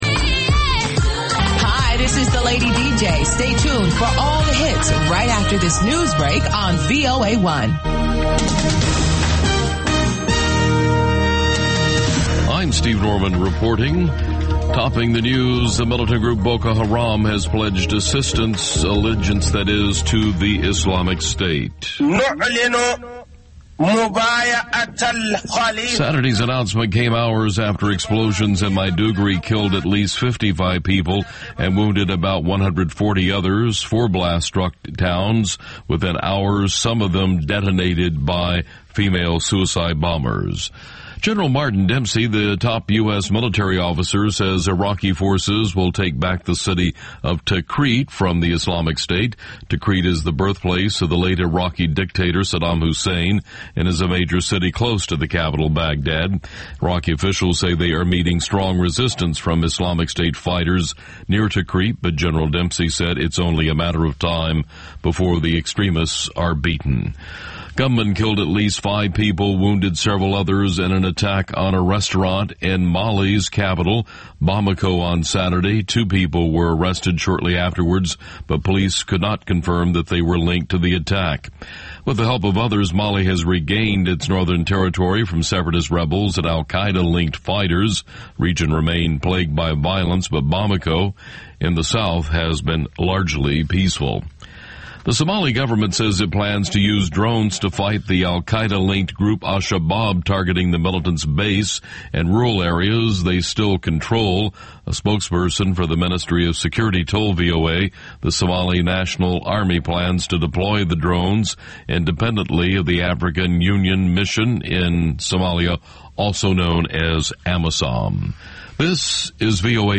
"ამერიკის ხმის" ახალი ამბები (ინგლისურად) + VOA Music Mix